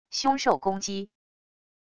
凶兽攻击wav音频